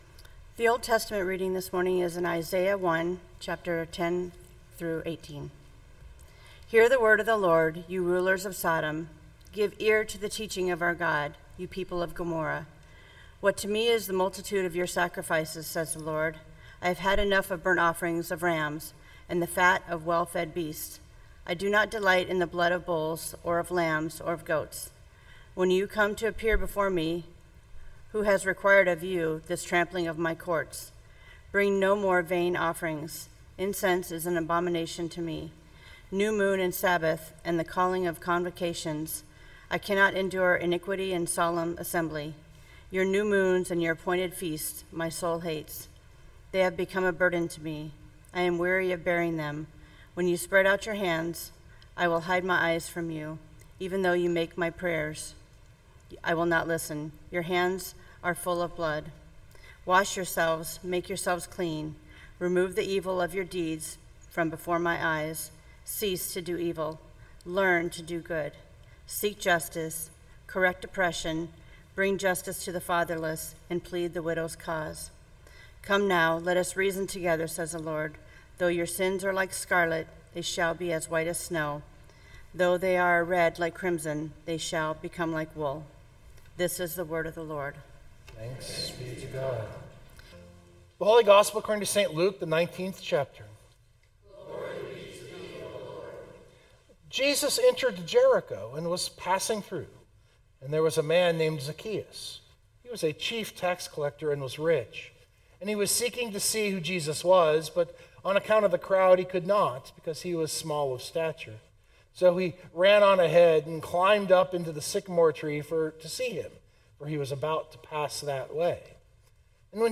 The sermon picks up from there.